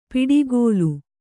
♪ piḍigōlu